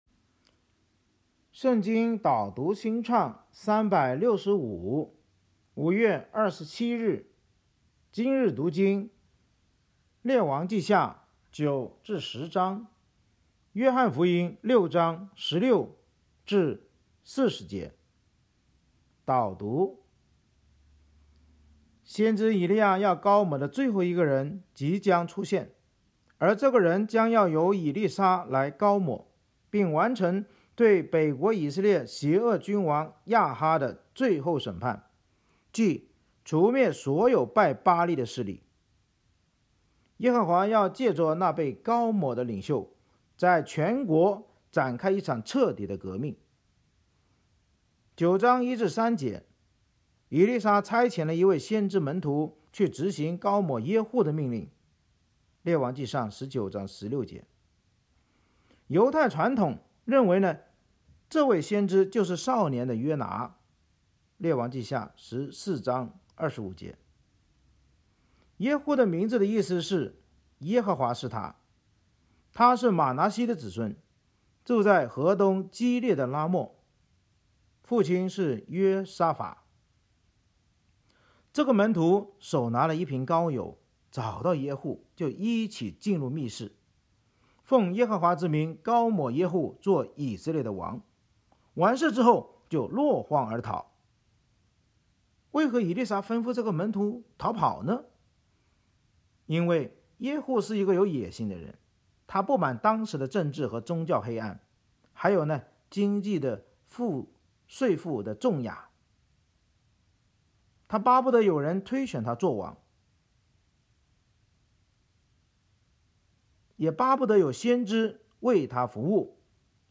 【经文朗读】